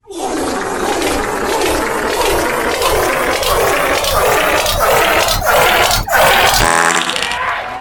Play Intense Fart - SoundBoardGuy
Play, download and share Intense Fart original sound button!!!!
intense-fart.mp3